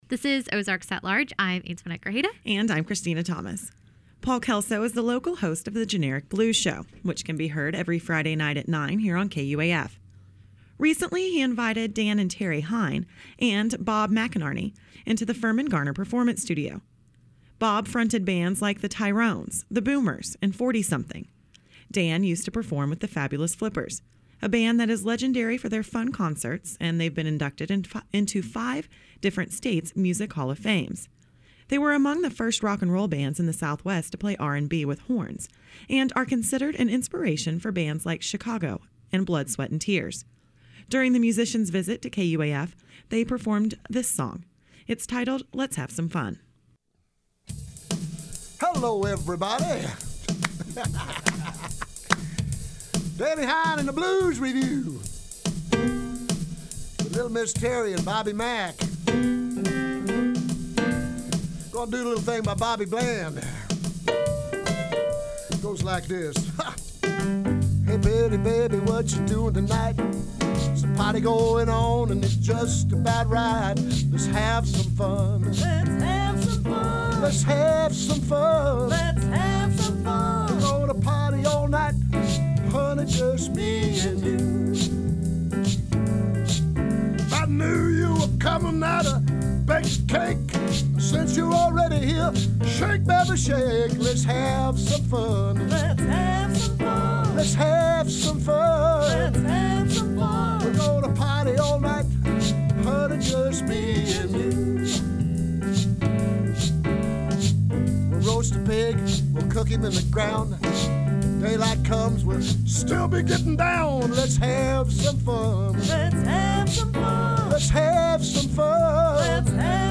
Trio Has Fun in Firmin-Garner Studio